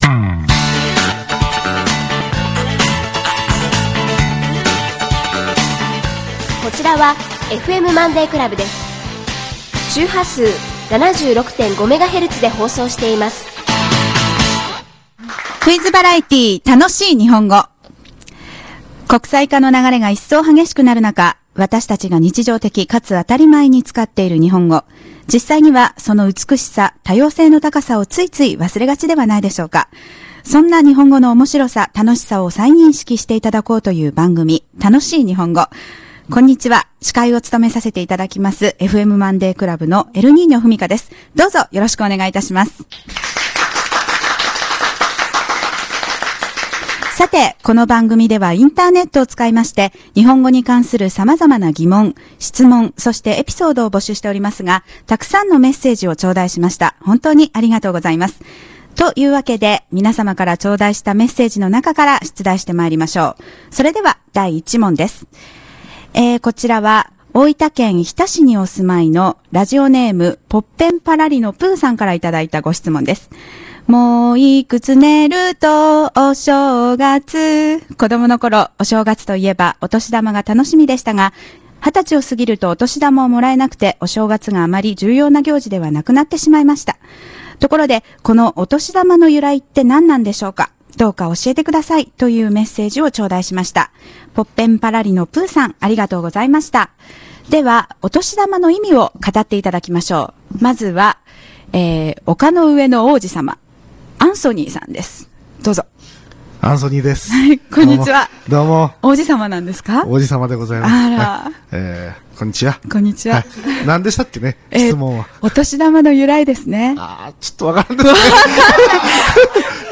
今週は完全ノーカット(CM付)でお送りします。